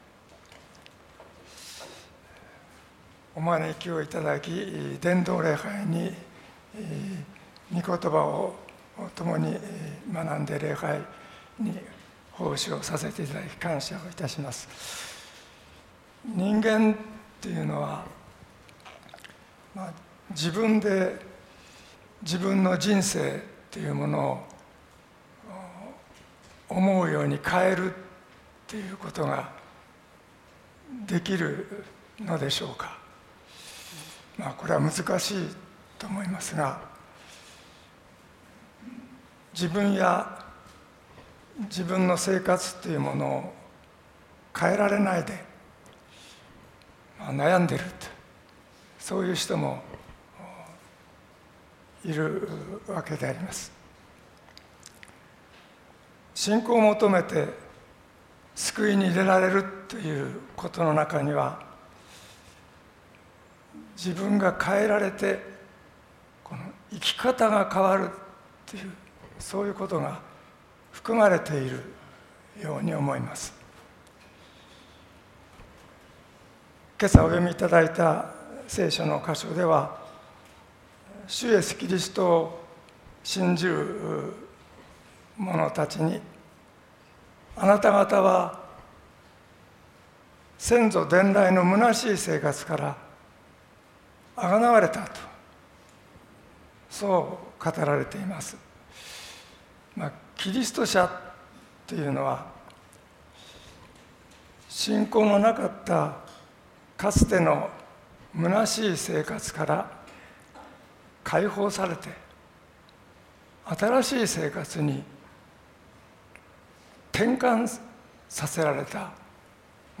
主日伝道礼拝